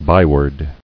[by·word]